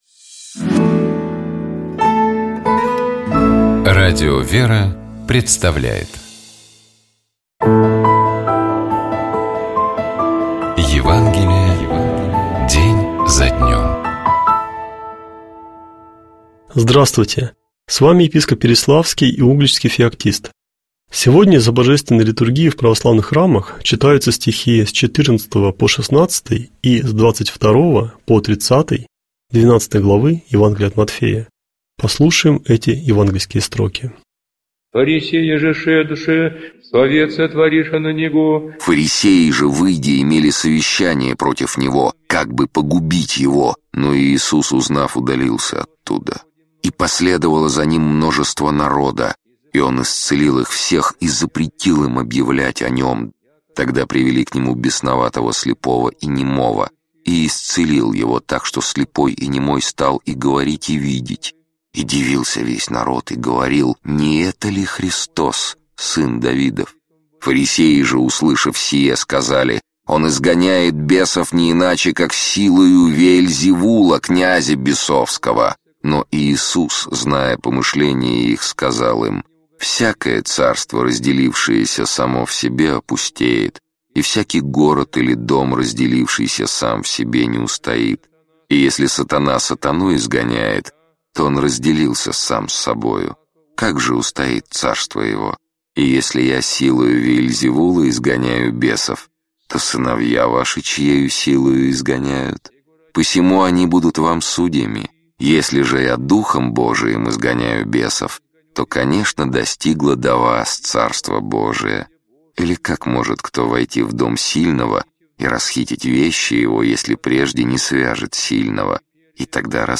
епископ Феоктист Игумнов Читает и комментирует епископ Переславский и Угличский Феоктист.